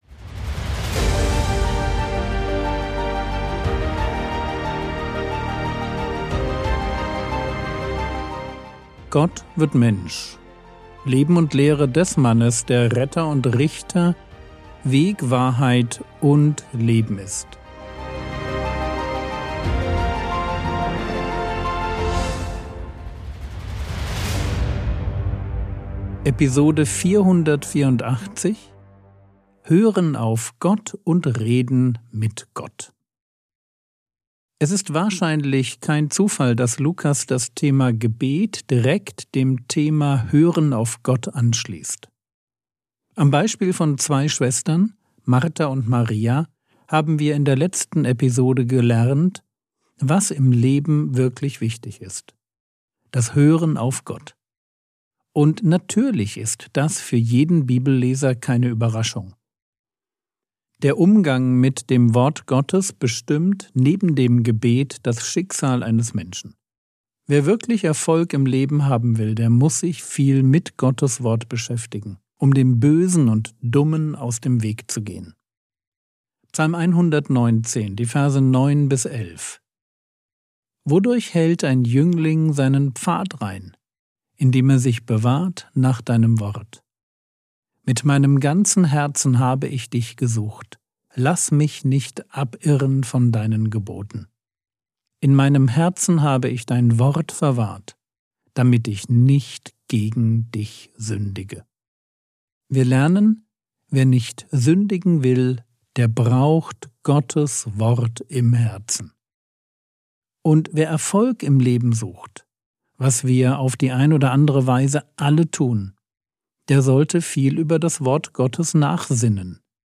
Episode 484 | Jesu Leben und Lehre ~ Frogwords Mini-Predigt Podcast